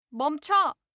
알림음 8_멈춰1-여자.mp3